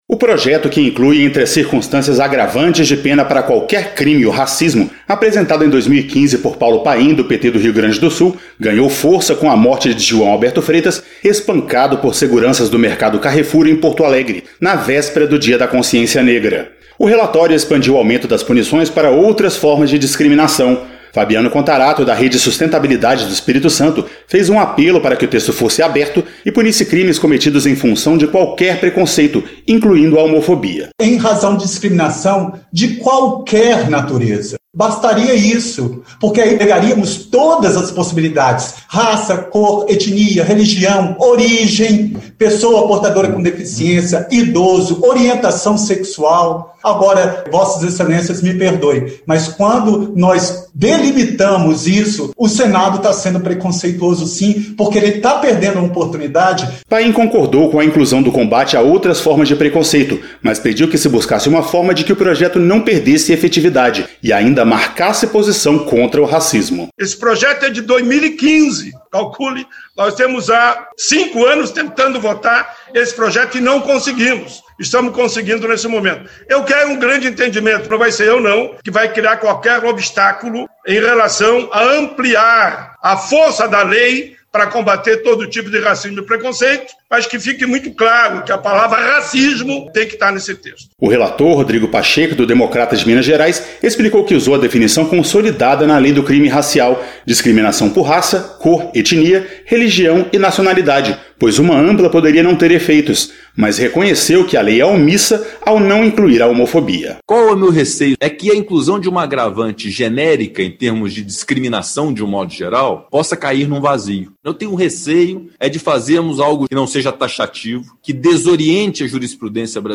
Durante a votação no plenário, Fabiano Contarato (Rede-ES) fez um apelo para incluir a homofobia e foi atendido. A reportagem